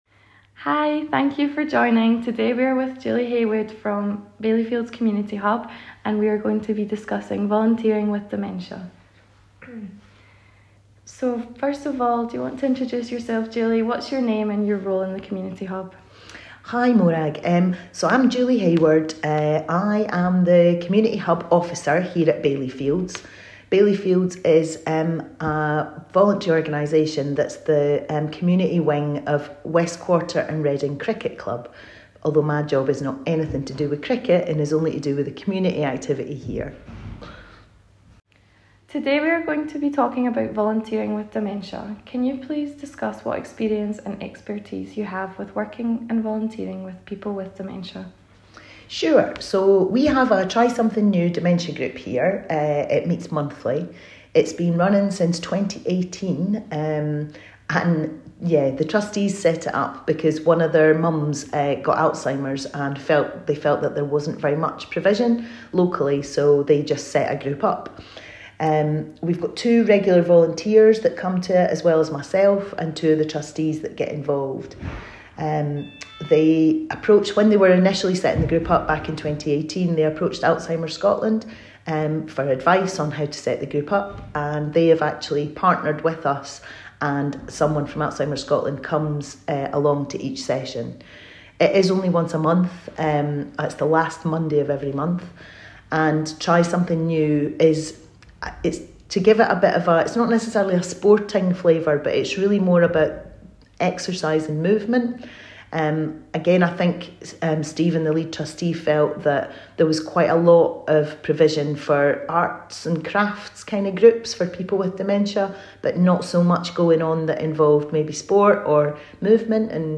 Community Garden Interview